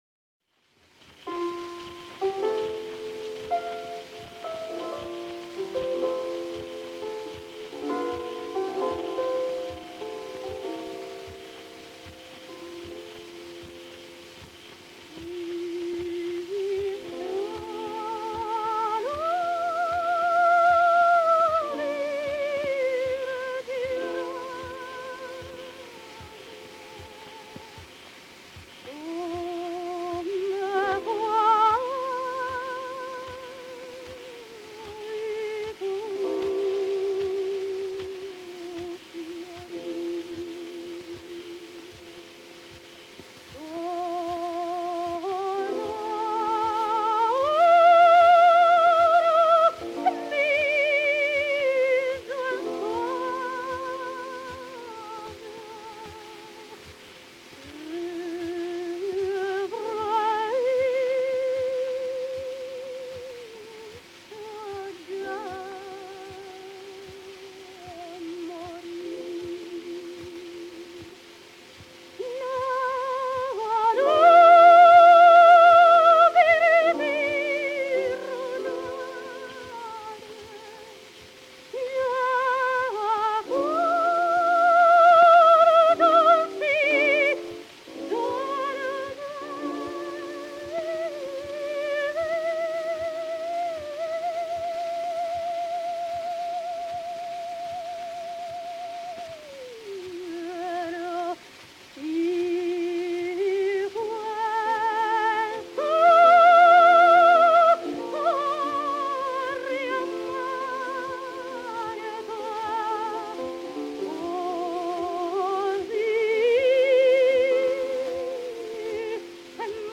Zo'n voorbeeld is de opname van "The Last Rose of Summer" gezongen door de zangeres Boronat. Luister vooral naar hoe zij controle heeft over ademsteun, de tonen verbindt al dan niet met gebruikmaking van portamento, in de ademsteun articuleert, hoge tonen veelal zonder grote druk met kopstem zingt en voortdurend richting geeft aan de melodie.
Italian Coloratura Soprano Olimpia Boronat  _ The Last Rose of Summer  (1904).mp3